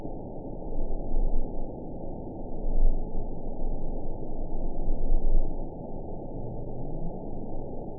event 920601 date 04/01/24 time 01:16:18 GMT (1 year, 1 month ago) score 9.03 location TSS-AB09 detected by nrw target species NRW annotations +NRW Spectrogram: Frequency (kHz) vs. Time (s) audio not available .wav